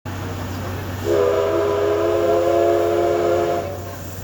〜車両の音〜
C11汽笛
207号機にて。